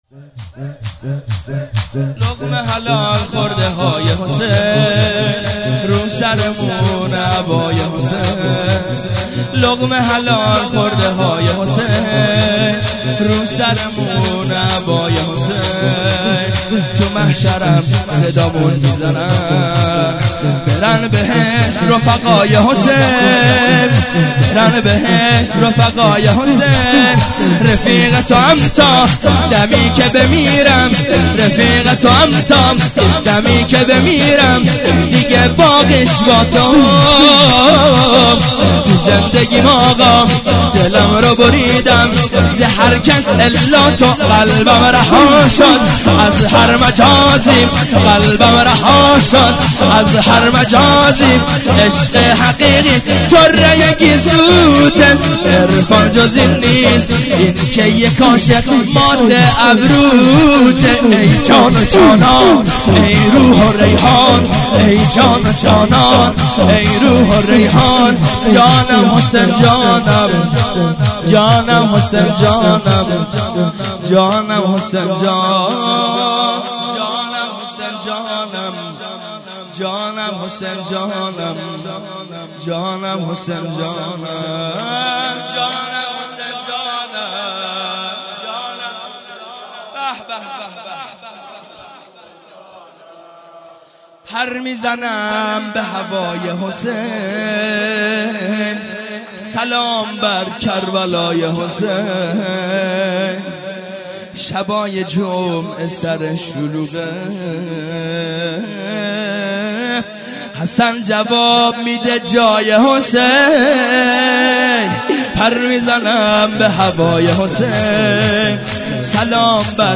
هیئت زواراباالمهدی(ع) بابلسر - شور - لقمه حلال خورده های حسین